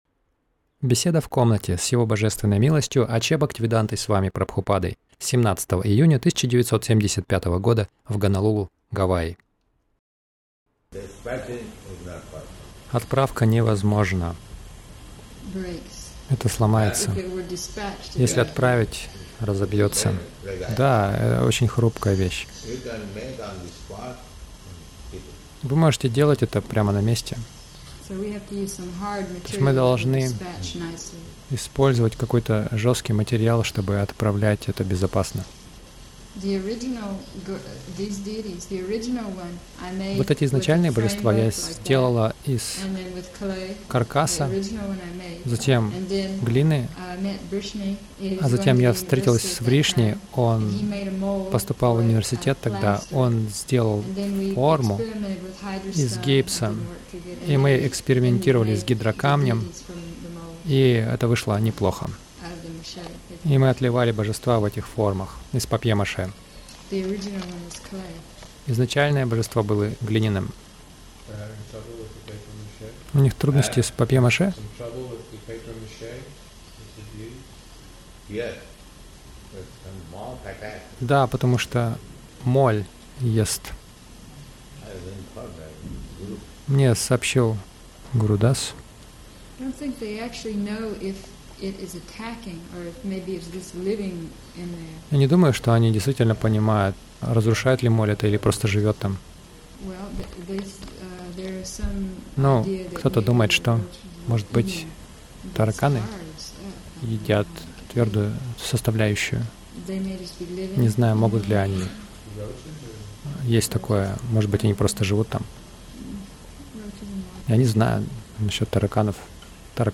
Беседа — Станьте самодостаточными